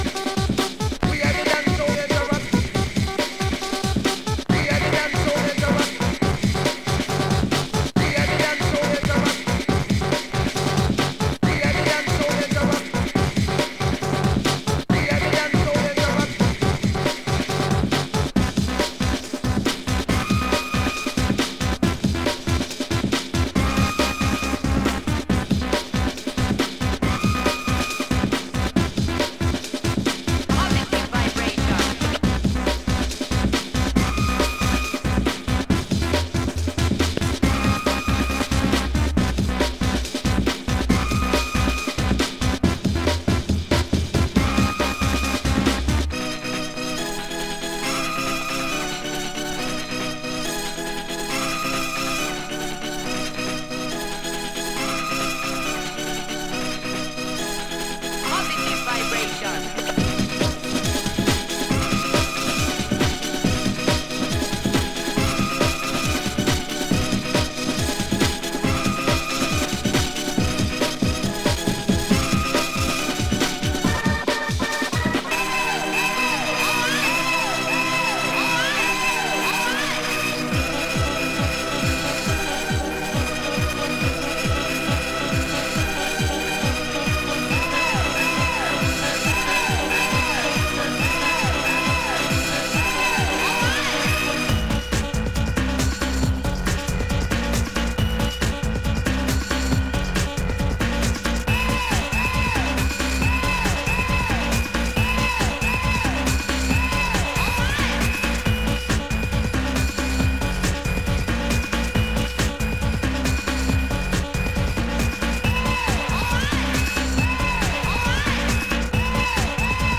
Surface marks and scratches cause some crackles on playback